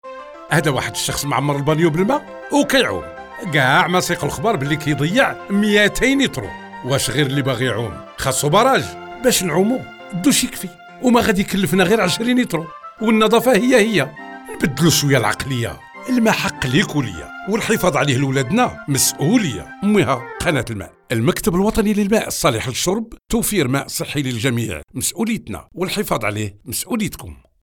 Spots radio: